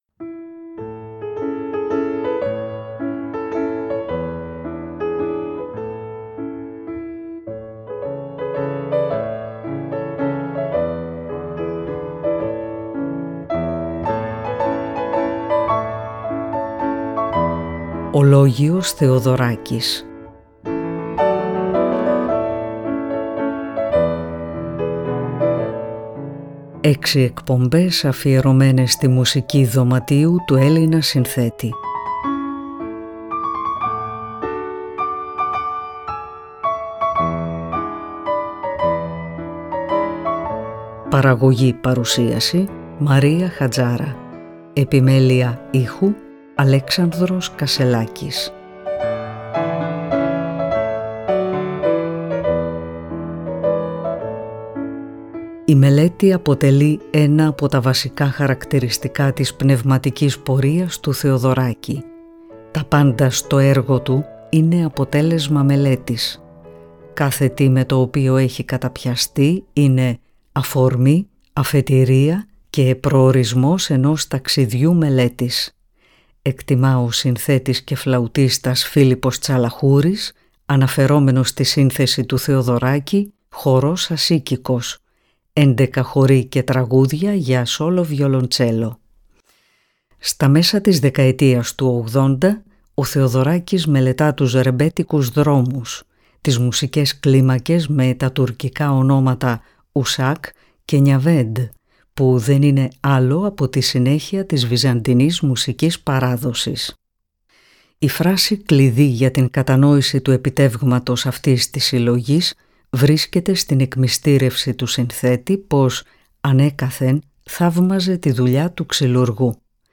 Έξι εκπομπές αφιερωμένες στη Μουσική Δωματίου